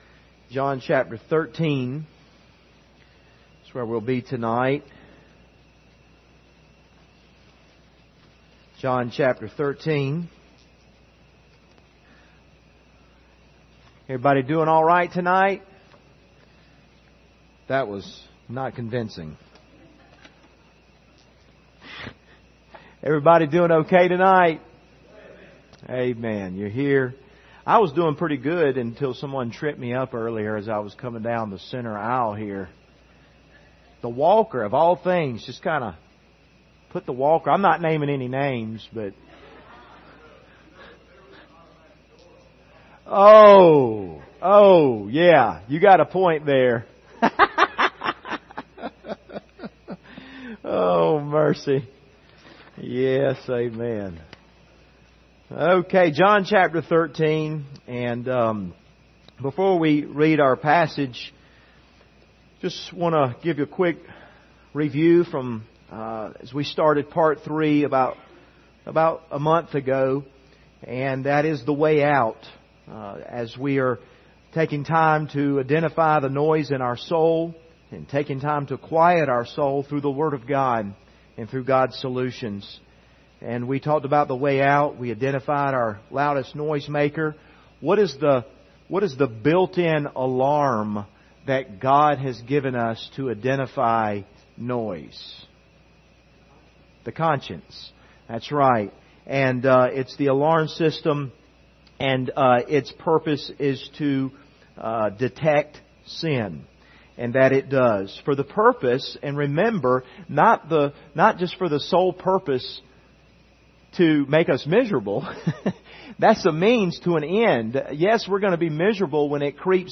Service Type: Wednesday Evening Topics: relationships